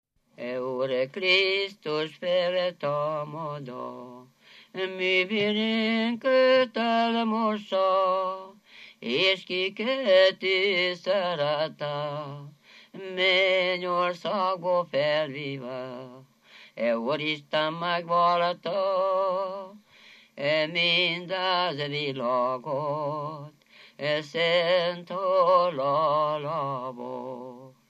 Moldva és Bukovina - Moldva - Klézse
ének
Stílus: 9. Emelkedő nagyambitusú dallamok
Kadencia: 1 (5) 2 1